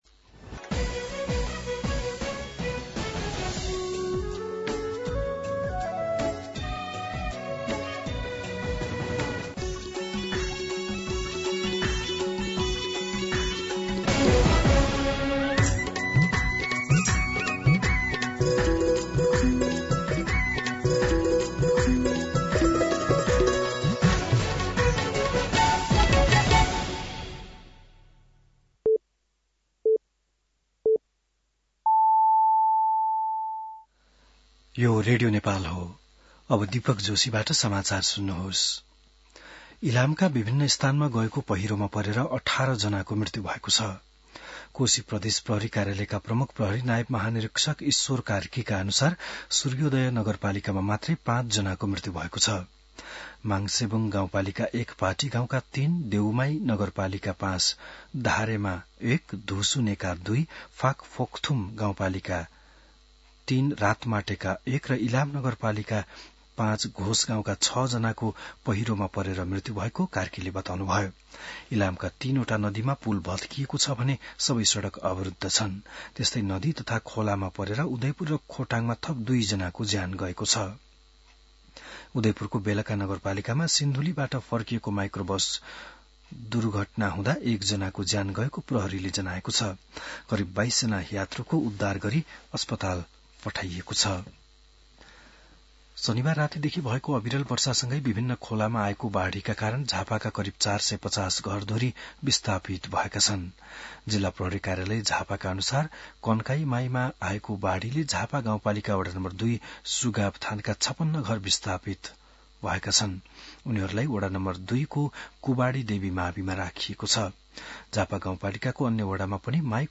बिहान ११ बजेको नेपाली समाचार : १९ असोज , २०८२
11am-News-19-1.mp3